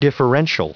Prononciation du mot : differential